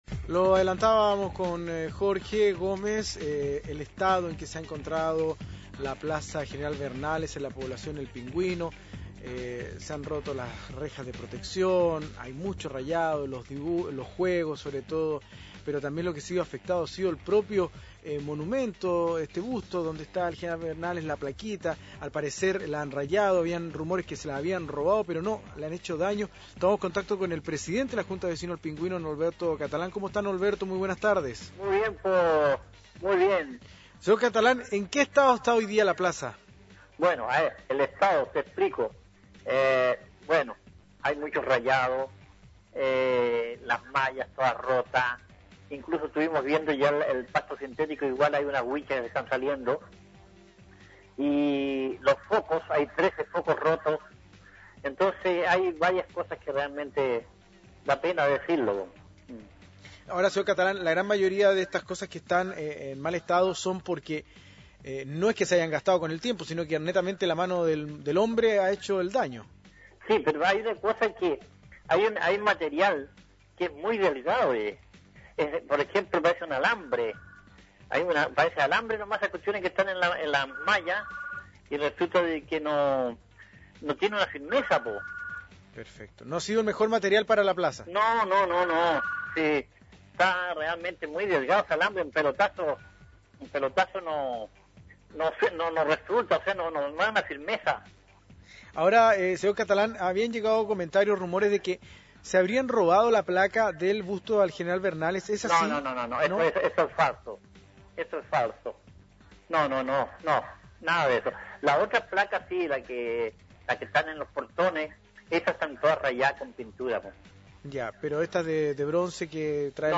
Entrevistas de Pingüino Radio
dirigente social